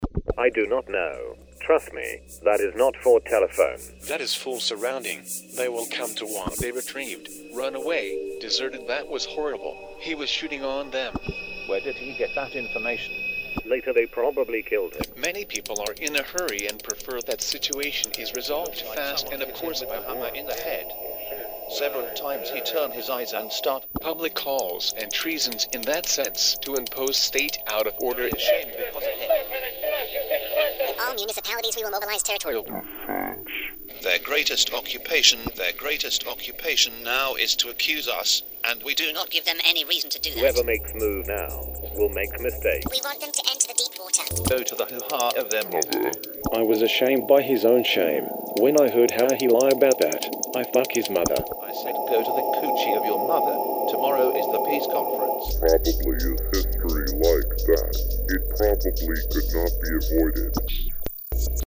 Sound installation
Sound installation is based on authentic telephone conversations of political and military authorities of Bosnia and Herzegovina in the moment when they are confronted with a situation of war.
It is one sonic illustration of historical events that took place in the telephone ether of Bosnia and Herzegovina in the period 1991- 1992.
Materials of telephone conversations where found at the web – page of the International Court of Justice of UN, who exactly recorded materials is unknown.